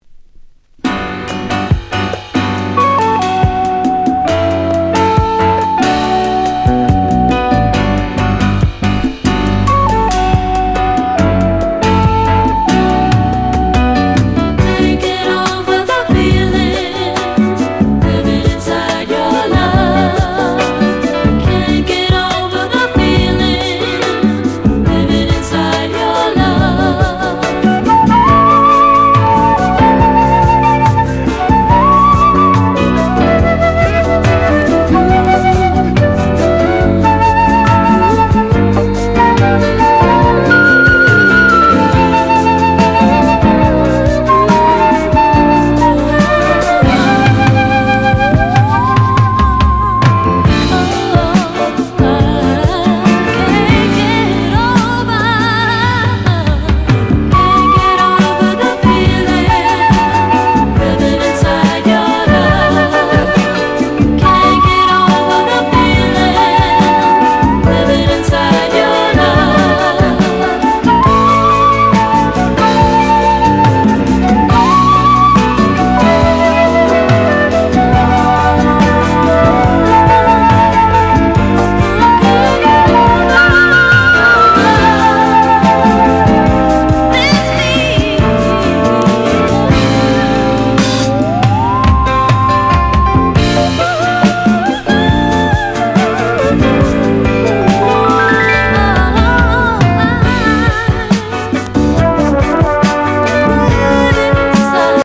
プログレッシヴ・フルート！
• 特記事項: STEREO